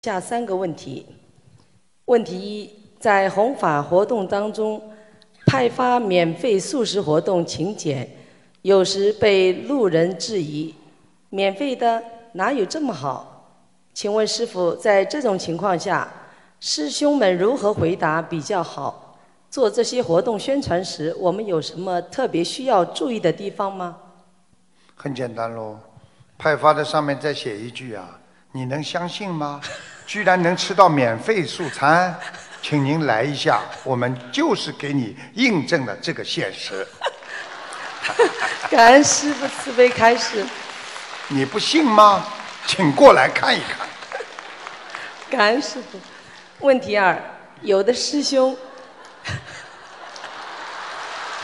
Pertanyaan di Seminar Dharma Auckland – Selandia Baru, 09 November 2019